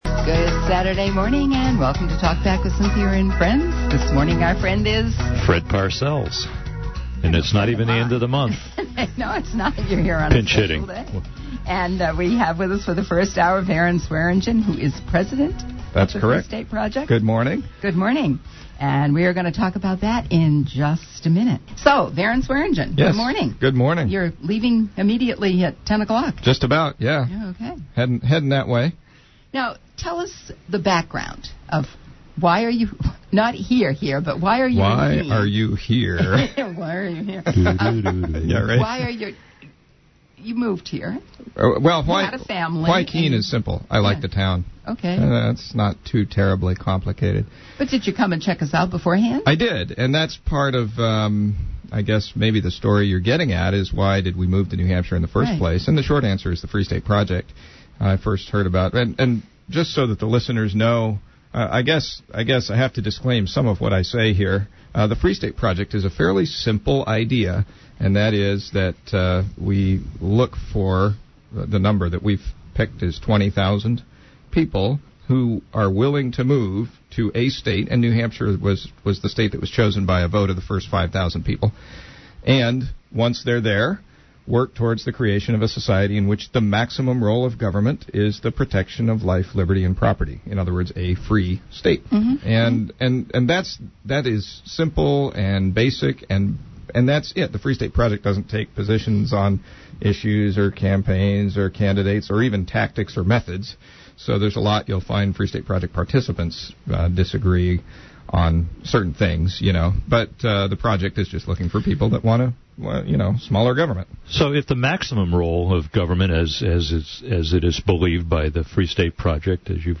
Also included in this archive are liberty activists calling later in the show and discussing the protectionist government system, confronting the system, and neighborly relations.